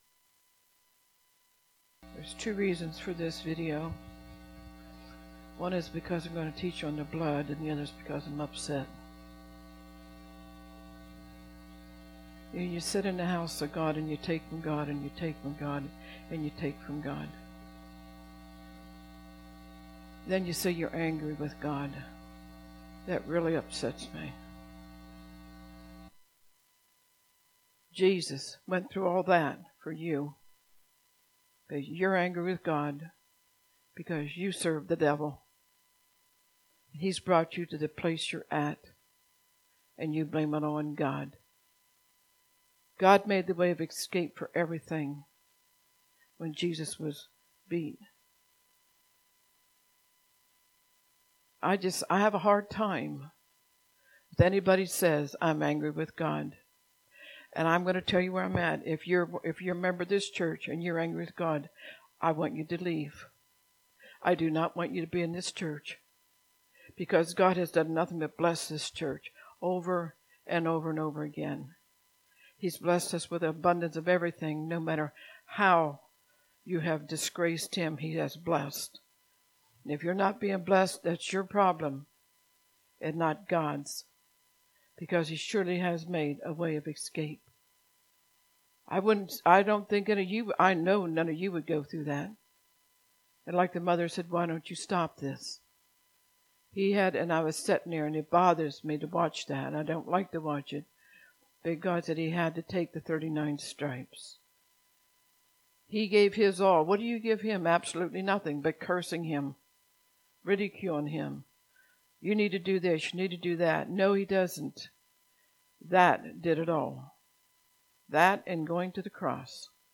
Posted in Sermons Tagged with atonement